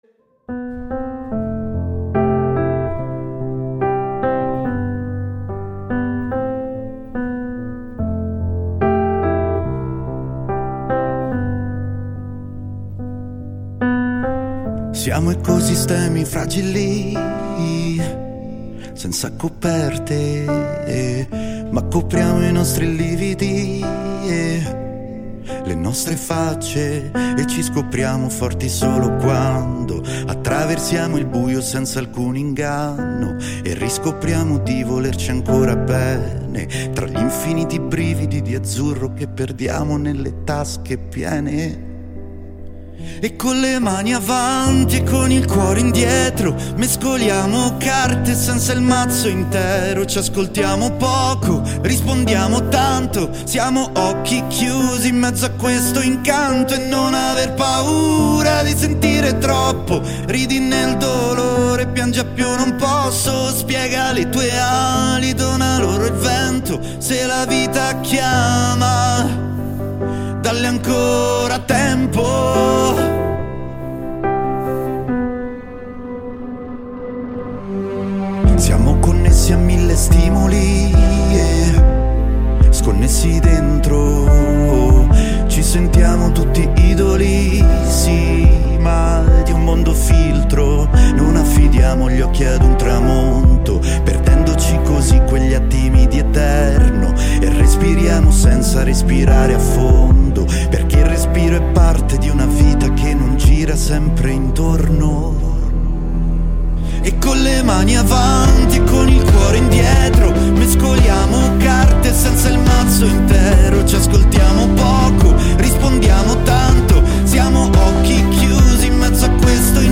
ARTISTA A LA VISTA | INTERVISTA